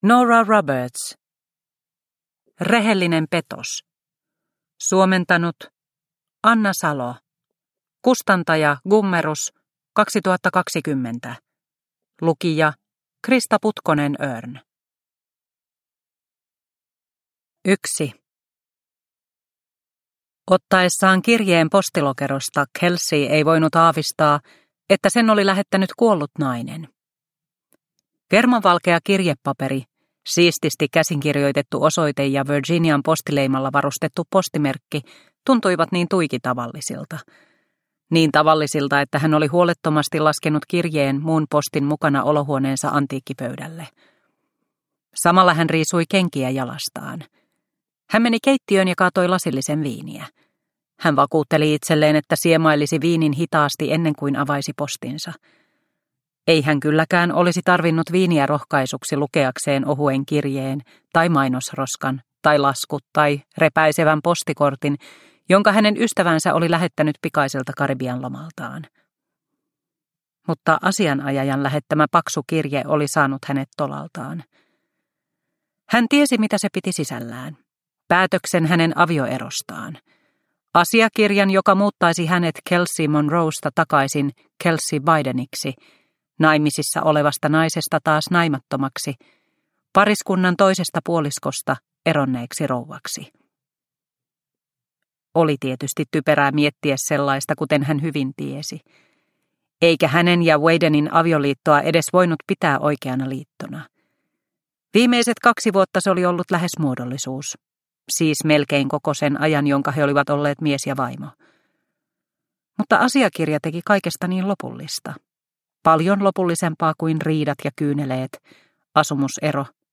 Rehellinen petos – Ljudbok – Laddas ner